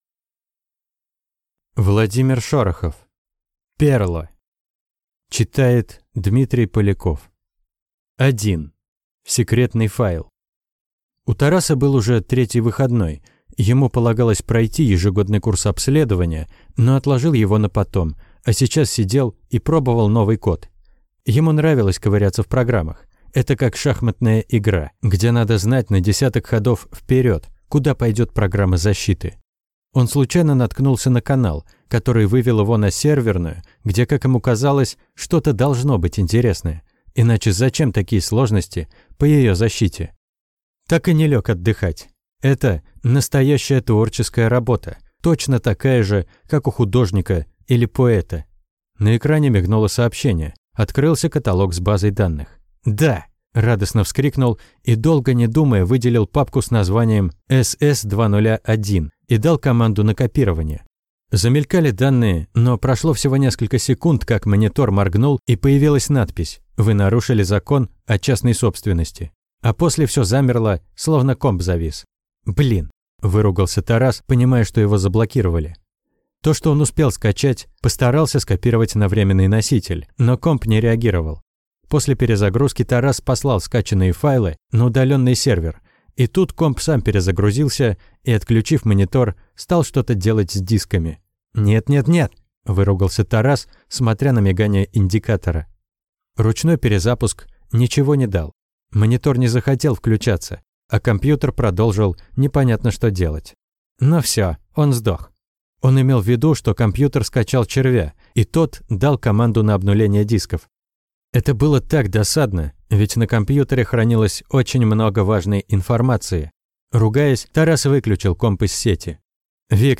Aудиокнига Перло